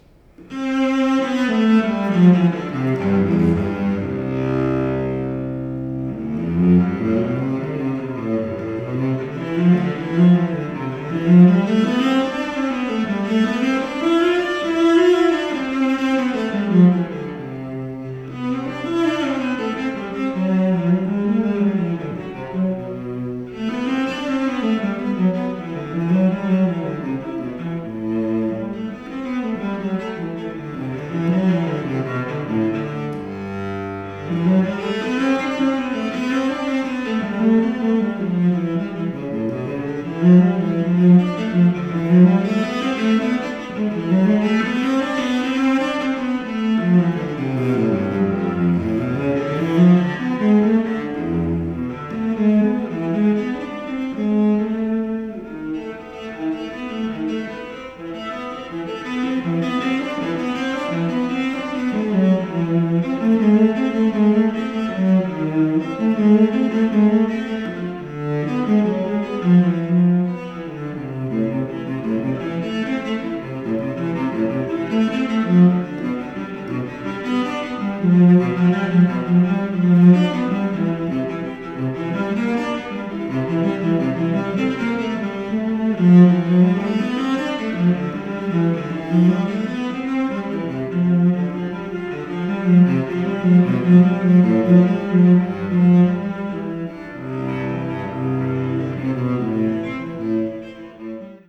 Cello
Listen to a Folland cello
Deep, powerful, and projecting.